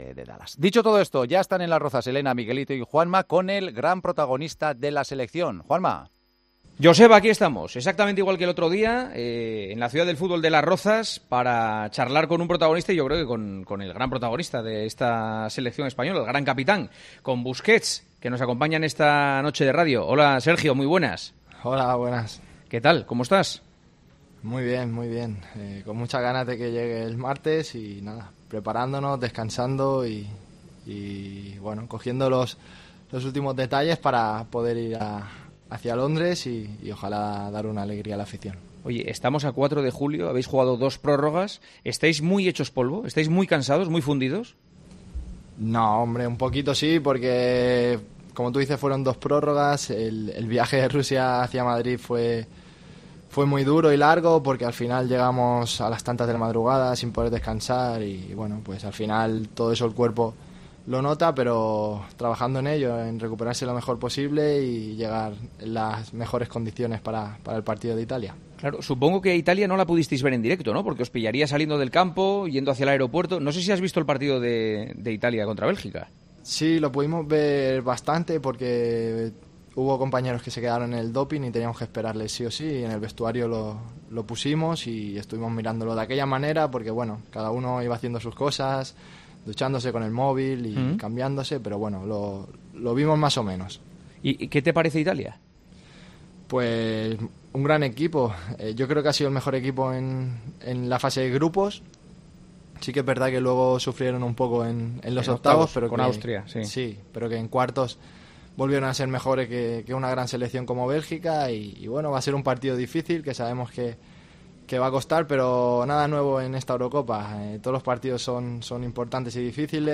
AUDIO: Hablamos con el capitán de la Selección Española del pase a semifinales y el partido ante Italia, su papel en el equipo de Luis Enrique y su futuro.